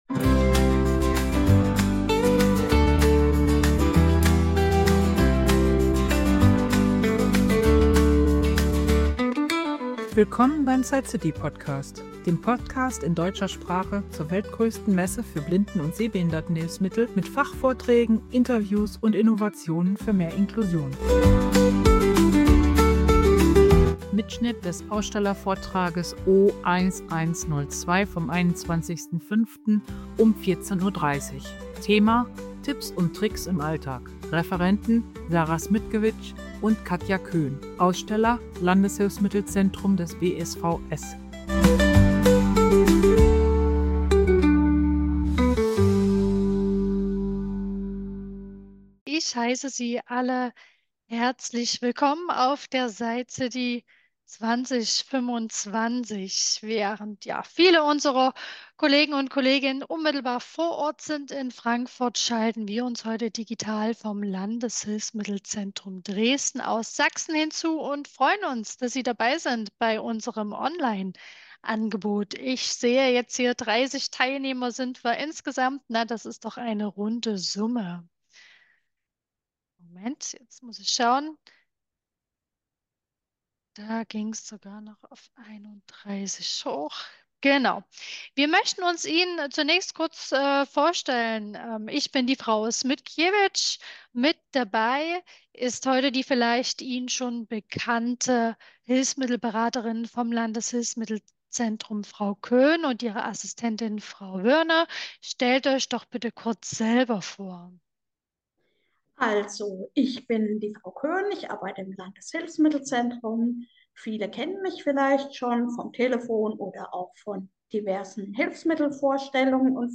SightCity Ausstellervortrag 2025: Tipps und Tricks im Alltag - LHZ (O1102) ~ SightCity DE Podcast